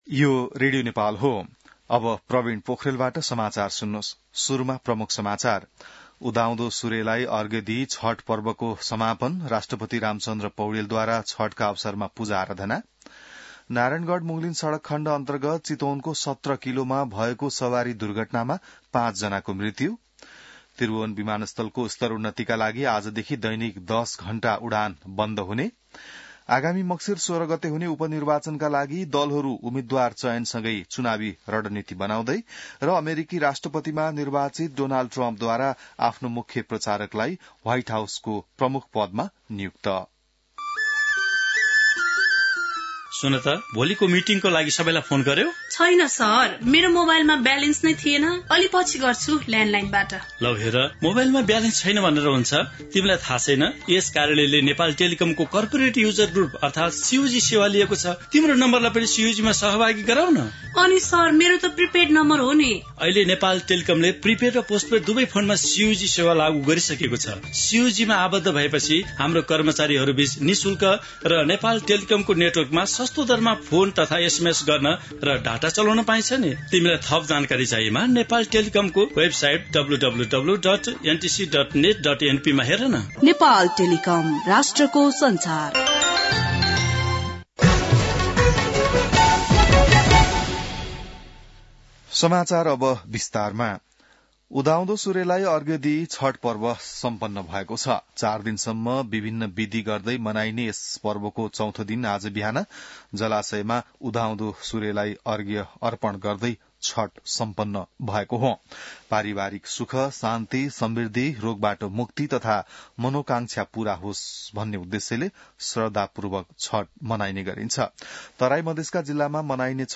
बिहान ७ बजेको नेपाली समाचार : २४ कार्तिक , २०८१